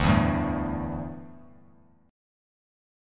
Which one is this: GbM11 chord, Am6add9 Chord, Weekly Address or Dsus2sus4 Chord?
Dsus2sus4 Chord